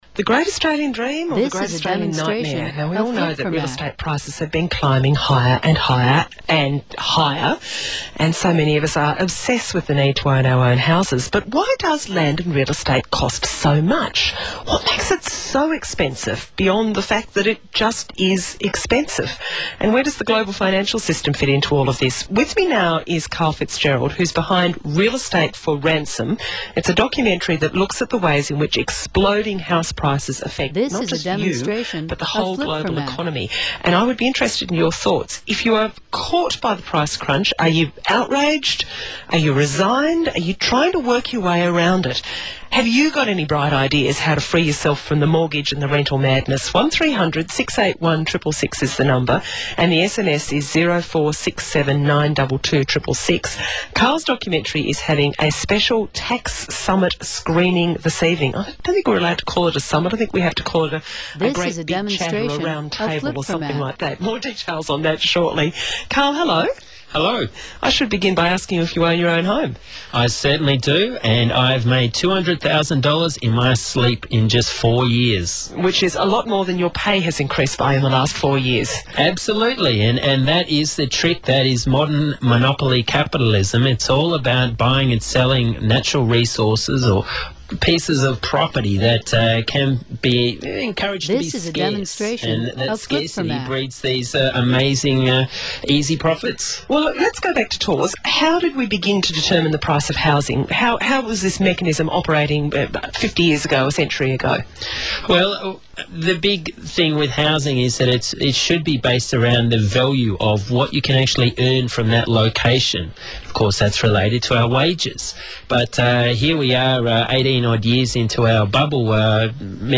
K2_ABCradio_canberra.mp3